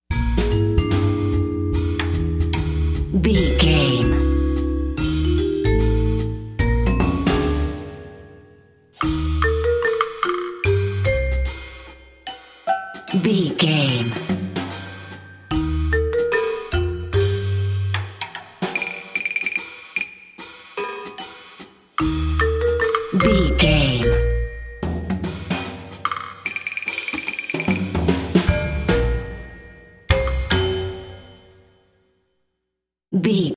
Ionian/Major
B♭
drums
percussion
double bass
silly
circus
goofy
comical
cheerful
perky
Light hearted
quirky